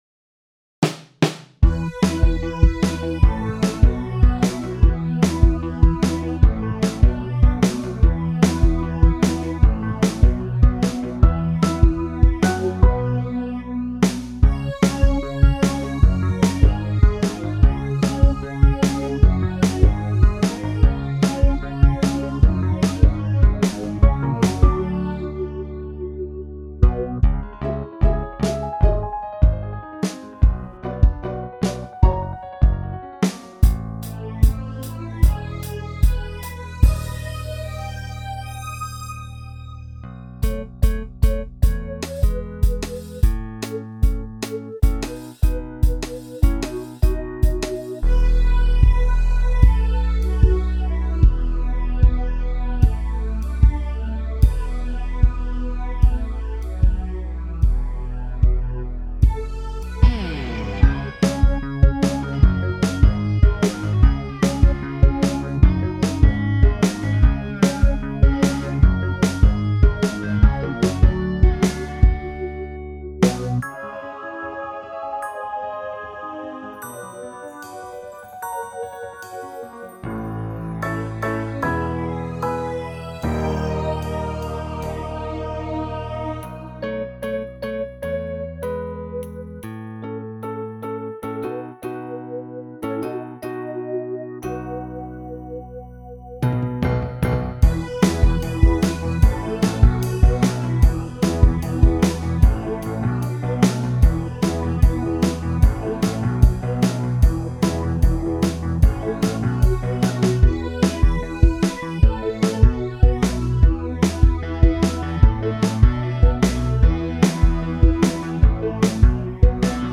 Sound icon This is mostly a pop piece, circa 1980, although the parts between the chorus are not as pop-ish.